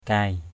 /kaɪ/ (đg.) gạt = ranger sur le côté. kai kagaok =k k_g<K gạt lửa = retirer la braise du feu et la ranger sur le côté. kai angah apuei =k...
kai.mp3